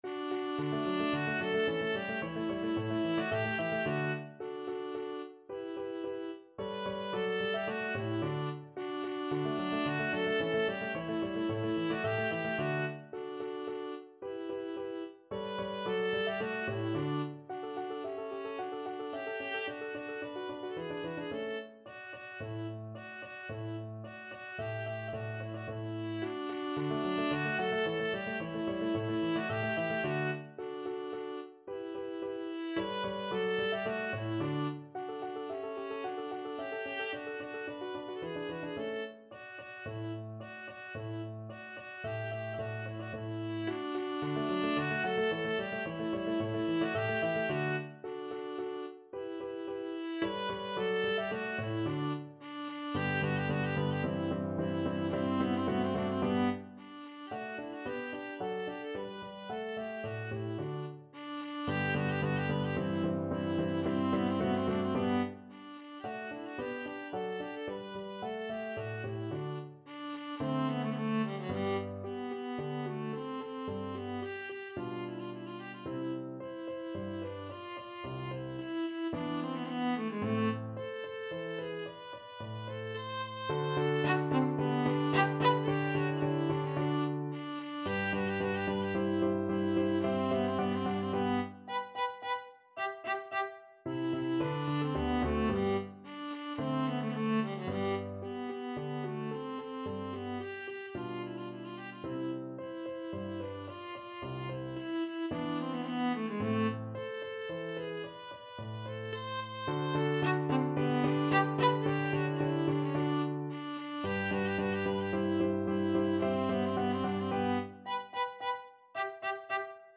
2/4 (View more 2/4 Music)
D major (Sounding Pitch) (View more D major Music for Viola )
Vivace assai =110 (View more music marked Vivace)
Viola  (View more Intermediate Viola Music)
Classical (View more Classical Viola Music)